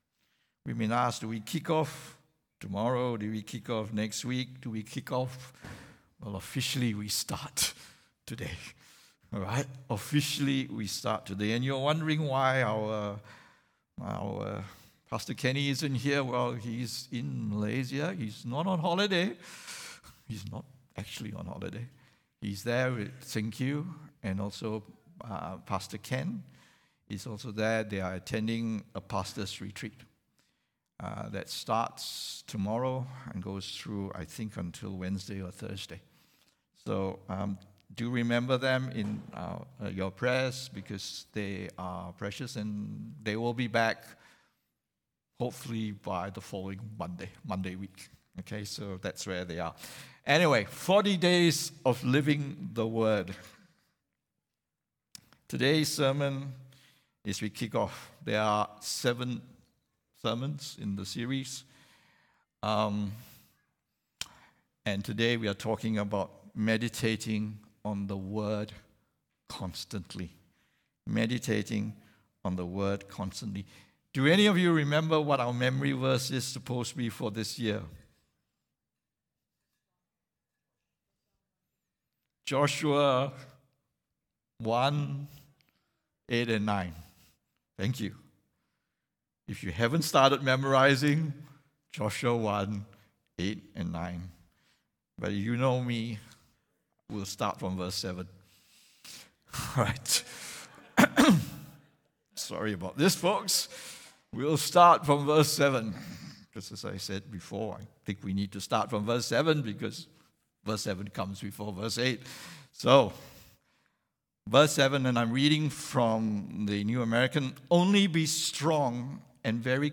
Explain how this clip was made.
English Worship Service - 19th February 2023